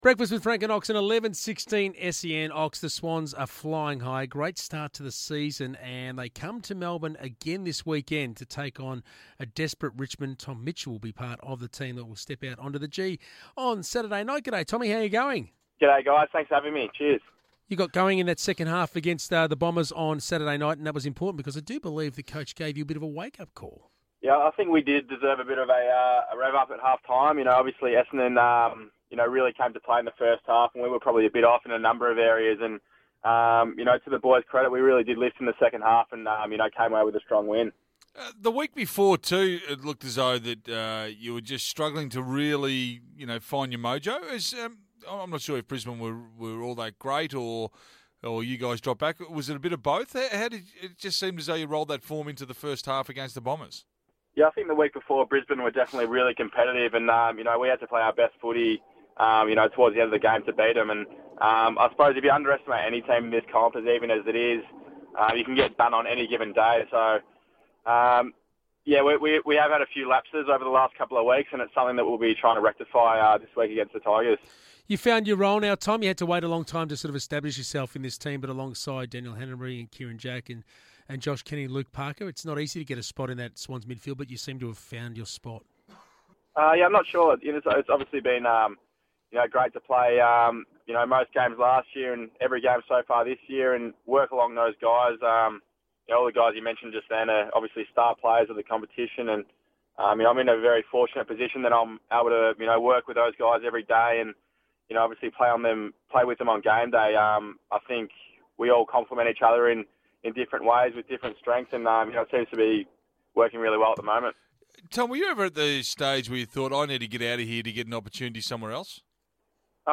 Midfielder Tom Mitchell speaks with Francis Leach and David Schwarz on SEN radio.